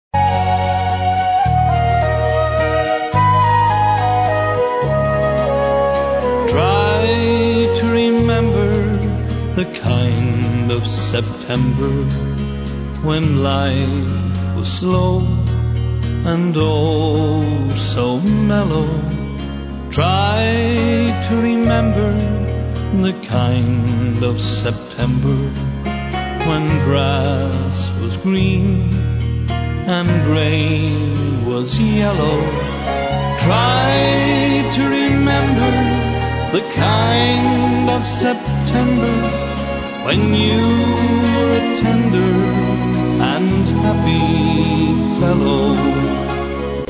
Нам понравился один л╦гкий менленный вальс, под который мы в танцшколе танцевали.
<----Здесь небольшой отрывок в плохом качестве (иначе слишком большой файл) Прикреплённые файлы #28